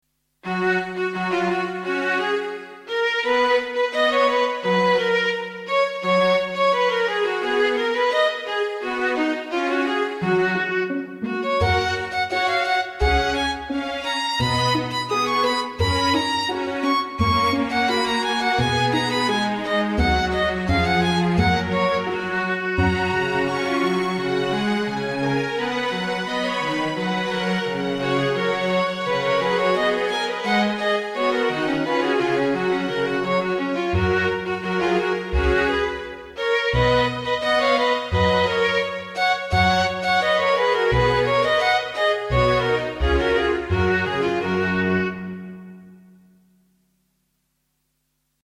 klassiek
Strijkorkest